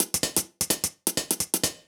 Index of /musicradar/ultimate-hihat-samples/128bpm
UHH_ElectroHatD_128-05.wav